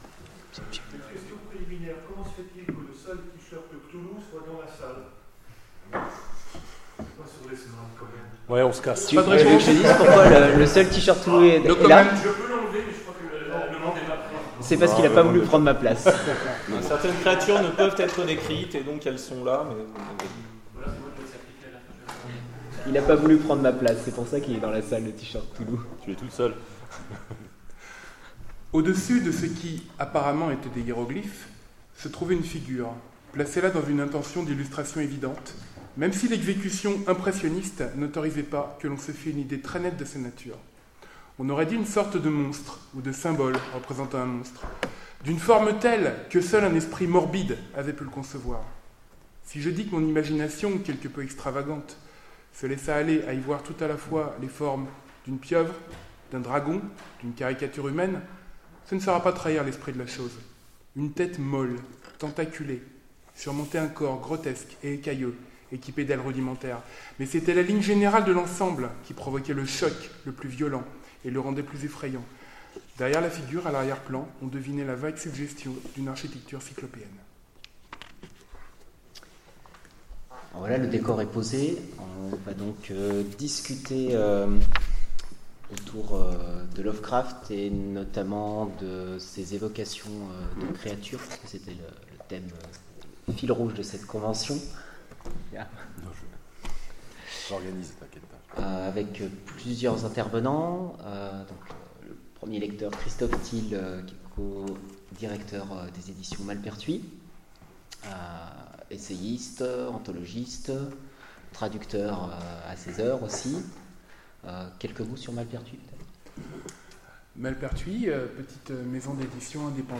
Convention SF 2017 : Table ronde et lectures Lovecraft
Conférence
Convention_sf_2017_conference_table_ronde_et_Lectures_ Lovecraft_ok.mp3